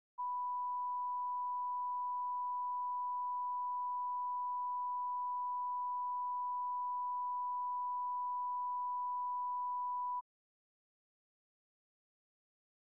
Secret White House Tapes
Conversation No. 804-2
Location: Oval Office
The President talked with Thelma C. (“Pat”) Nixon.